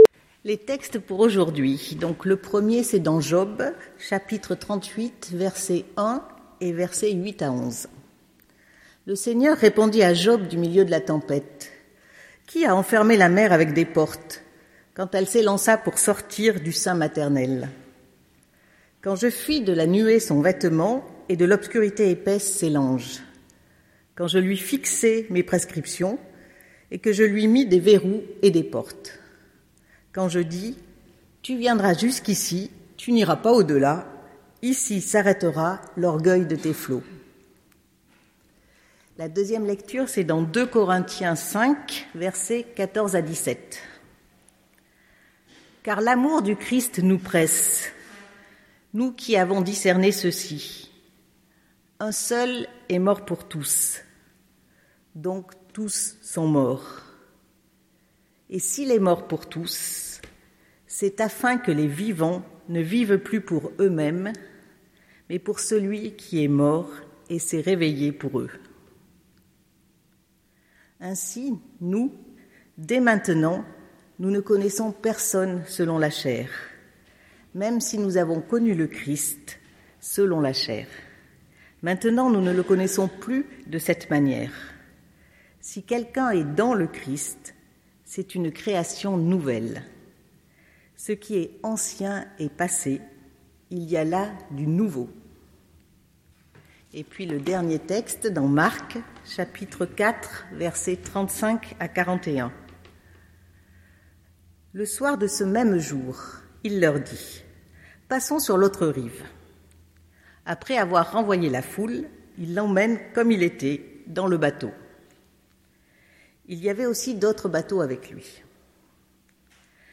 PREDICATION 21 JUILLET